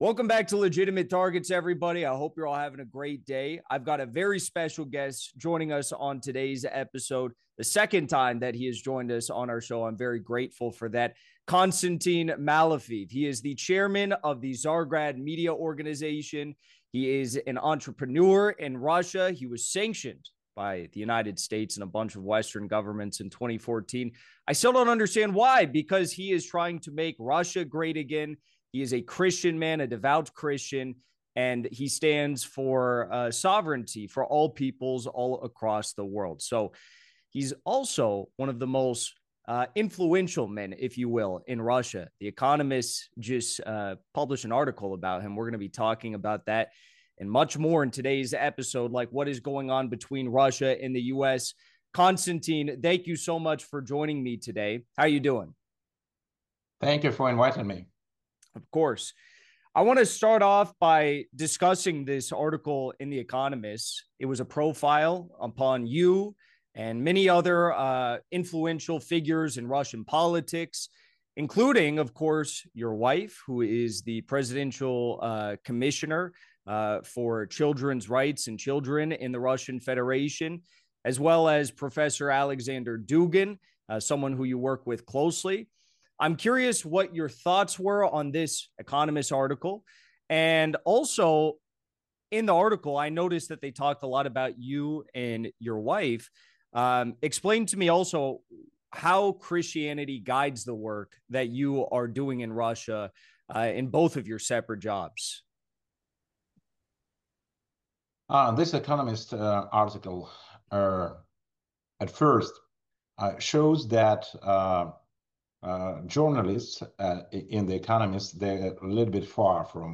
Jackson Hinkle interviews SANCTIONED Russian Christian Businessman KONSTANTIN MALOFEEV on RUSSIA’S BATTLEFIELD VICTORIES, US-Russia negotiations & his new profile in the Economist